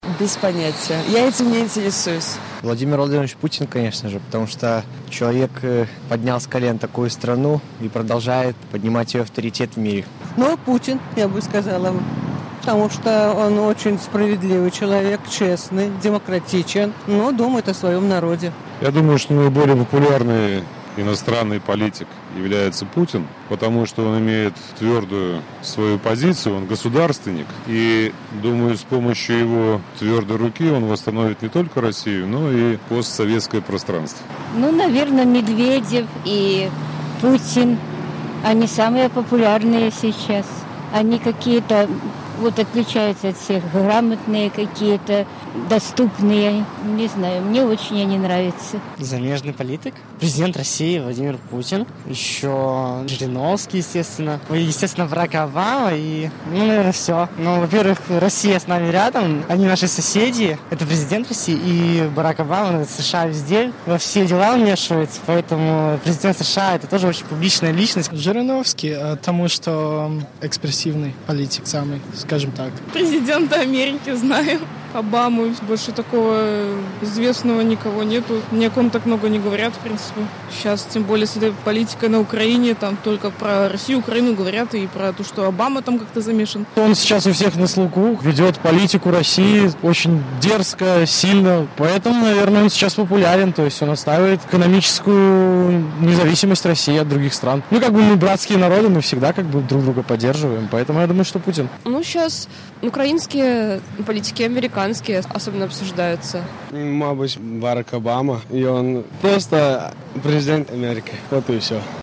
Адказваюць выпадковыя менскія мінакі